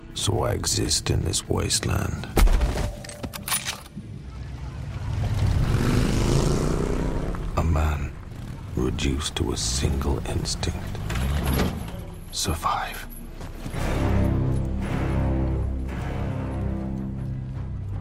man.mp3